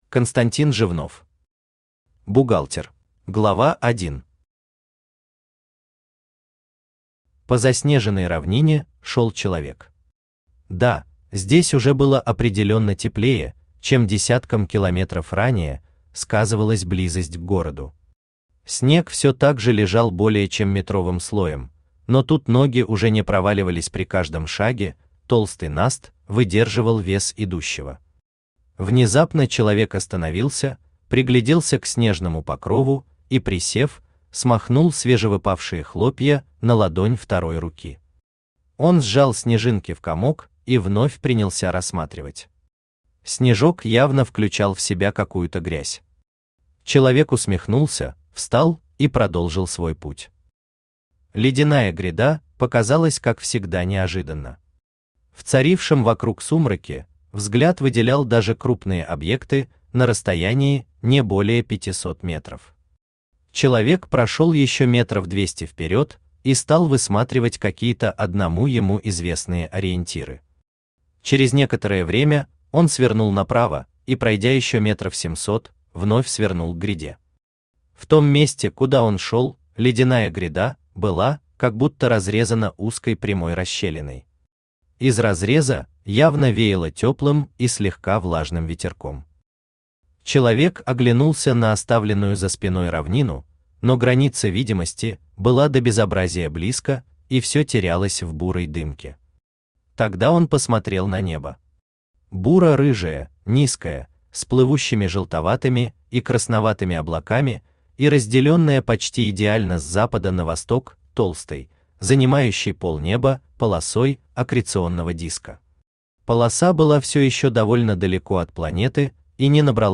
Аудиокнига Бухгалтер | Библиотека аудиокниг
Aудиокнига Бухгалтер Автор Константин Александрович Жевнов Читает аудиокнигу Авточтец ЛитРес.